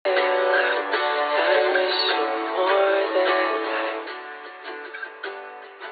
Когда ты Джастин Бибер и кому-то пришло уведомление на студии)